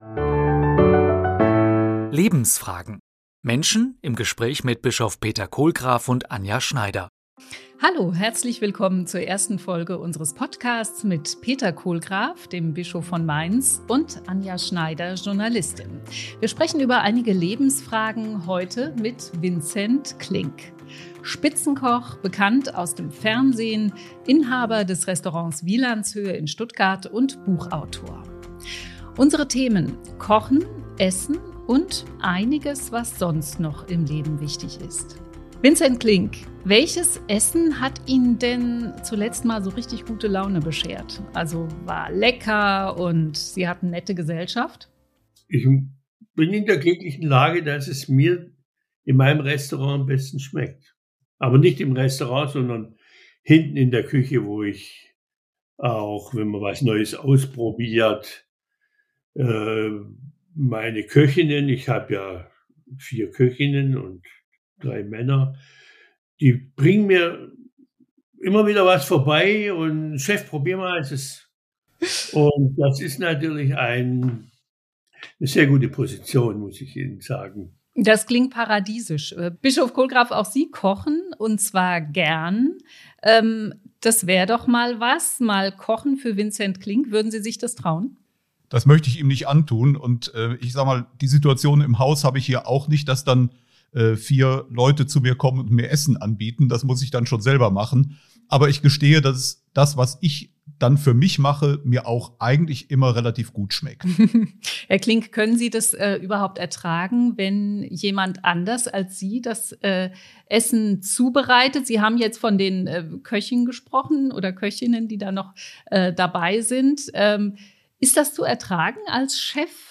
Zu Gast: Vincent Klink.